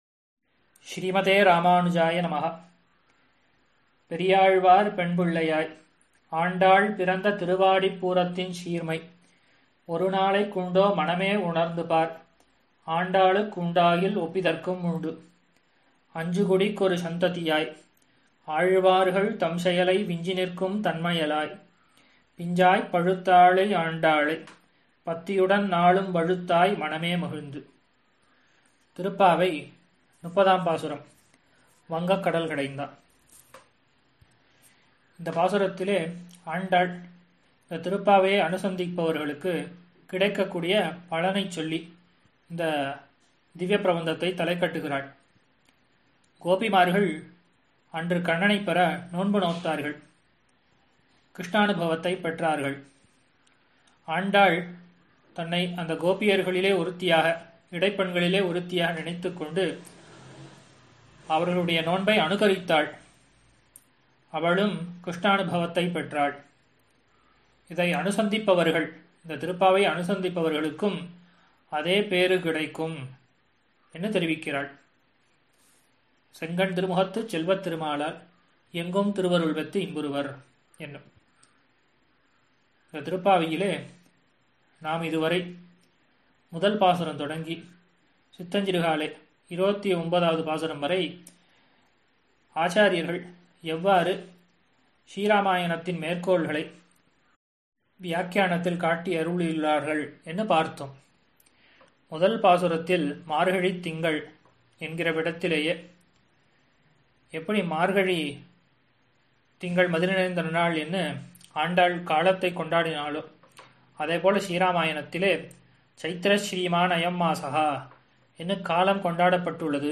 மார்கழி ௴ உபன்யாசம்